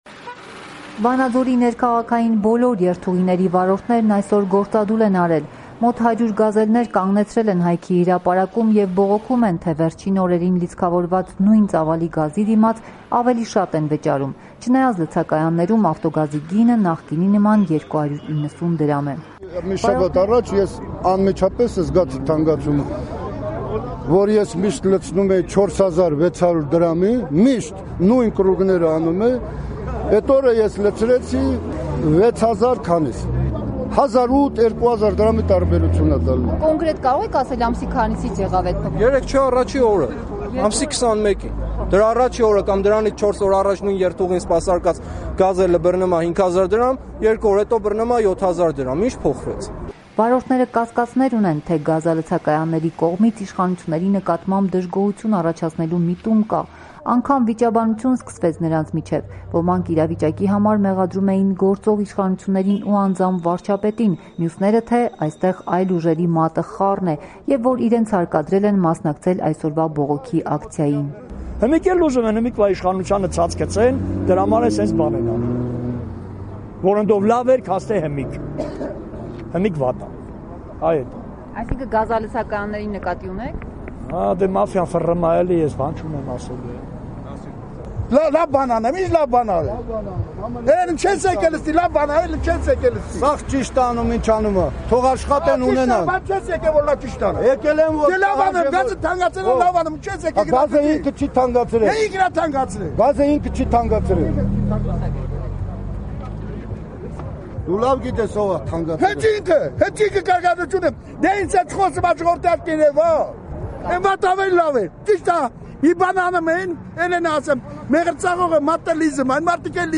Ռեպորտաժներ